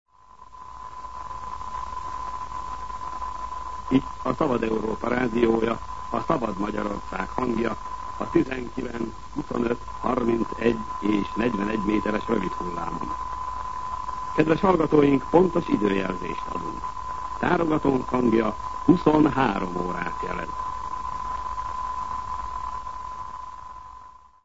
Tárogatónk hangja 23 órát jelez.